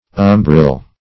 umbril - definition of umbril - synonyms, pronunciation, spelling from Free Dictionary Search Result for " umbril" : The Collaborative International Dictionary of English v.0.48: umbril \um"bril\ ([u^]m"br[i^]l), n. An umbrere.